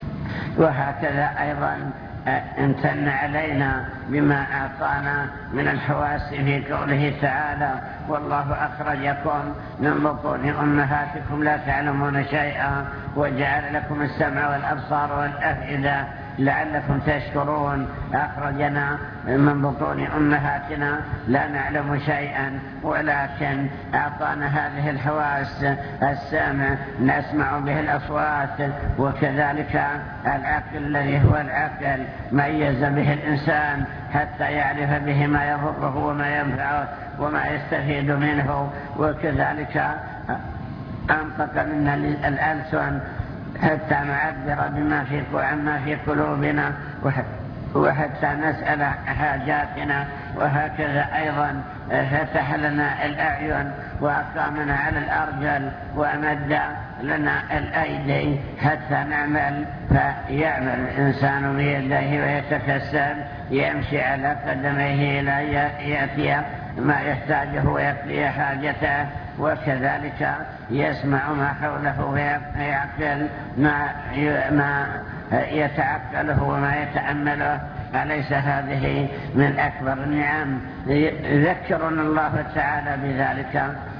المكتبة الصوتية  تسجيلات - محاضرات ودروس  محاضرة بعنوان شكر النعم (2) امتنان الله تعالى على عباده بما أنعم عليهم